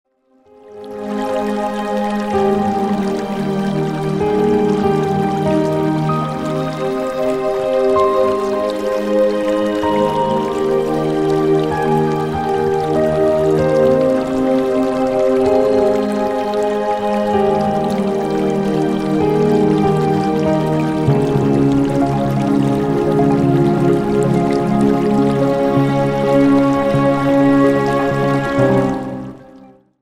RELAXATION MUSIC  (04.14)